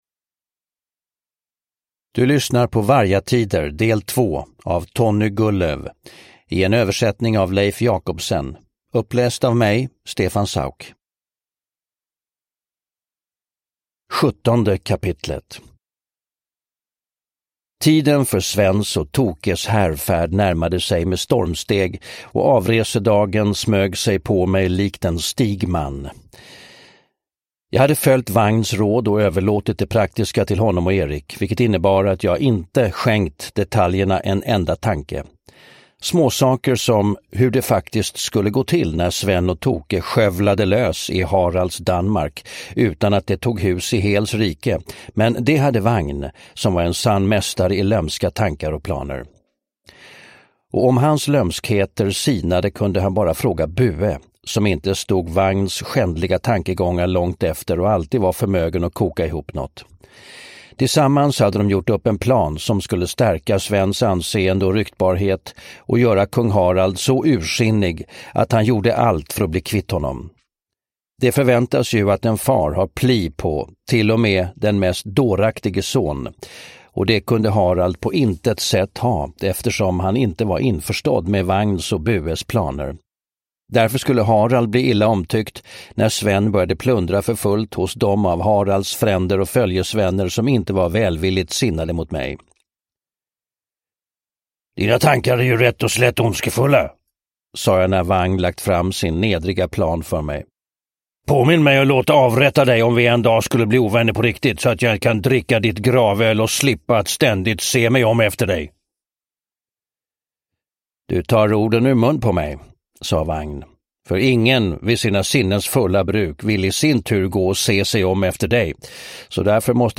Vargatider del 2 (ljudbok) av Tonny Gulløv